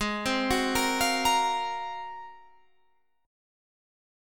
Ab13 Chord
Listen to Ab13 strummed